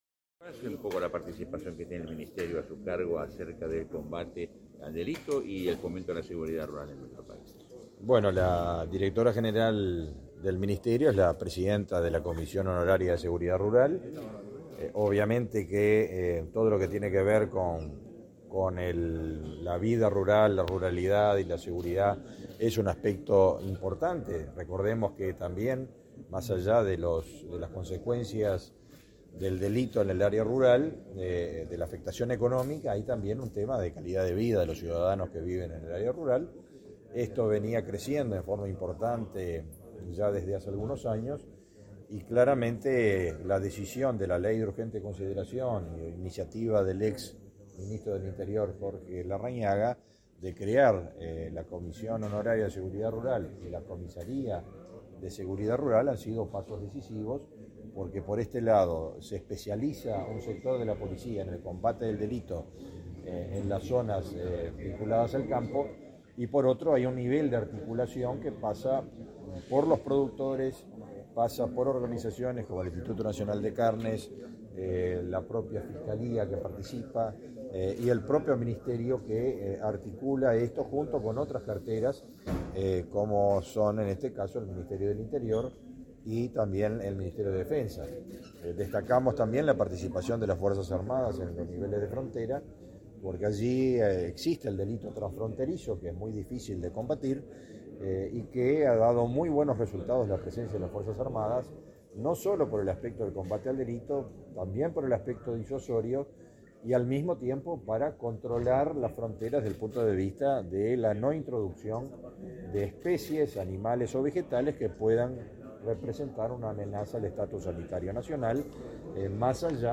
Declaraciones a la prensa del ministro de Ganadería, Fernando Mattos
Declaraciones a la prensa del ministro de Ganadería, Fernando Mattos 17/03/2022 Compartir Facebook X Copiar enlace WhatsApp LinkedIn El ministro de Ganadería, Fernando Mattos, dialogó con la prensa, luego del taller que organizó este jueves 17 la Comisión Asesora Honoraria para la Seguridad Rural.